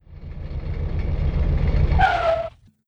Título: Carro
Palavras-chave: Jogos educacionais; Foley
moto de carro indo e brecando.wav